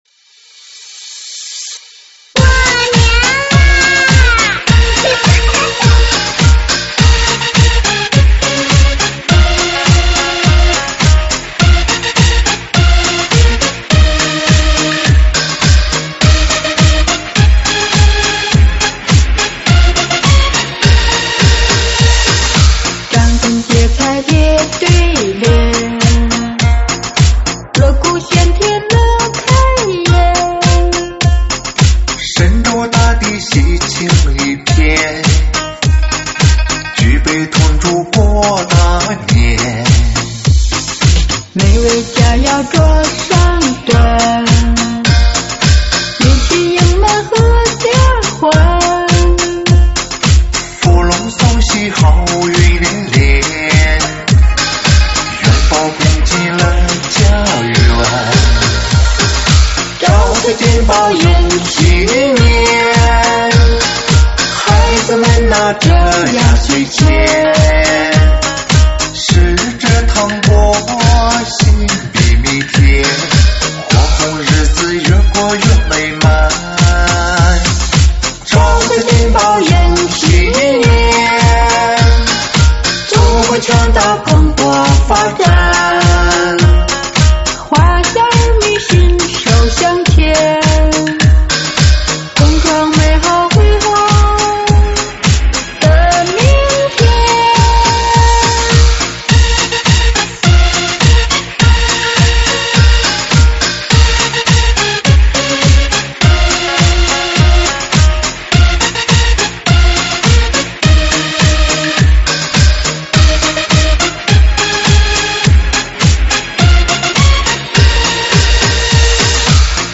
舞曲类别：新年喜庆